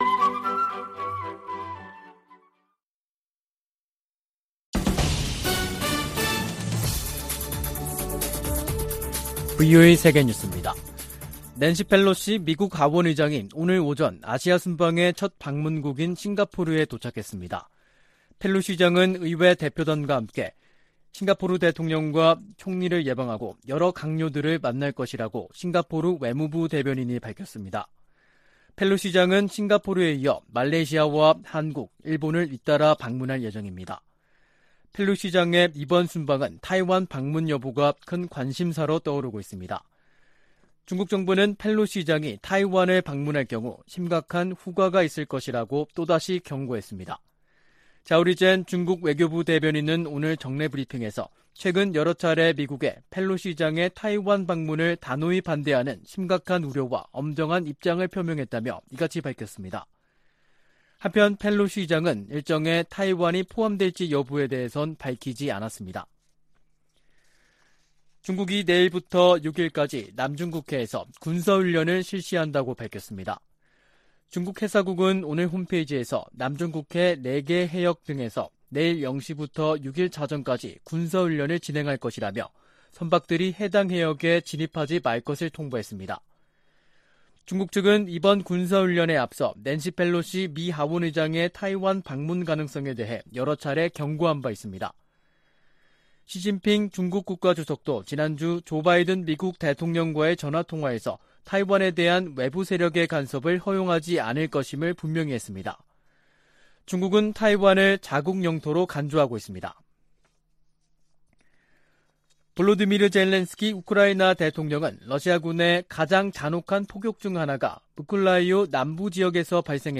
VOA 한국어 간판 뉴스 프로그램 '뉴스 투데이', 2022년 8월 1일 3부 방송입니다. 백악관 국가안보회의(NSC) 고위관리가 미-한 연합훈련과 관련해 준비태세의 중요성을 강조하고, 한반도 상황에 맞게 훈련을 조정하고 있다고 밝혔습니다. 미 국방부는 중국의 사드 3불 유지 요구와 관련해 한국에 대한 사드 배치는 두 나라의 합의에 따라 결정될 것이라는 입장을 밝혔습니다. 밥 메넨데즈 미 상원 외교위원장이 '쿼드'에 한국을 포함해야 한다고 말했습니다.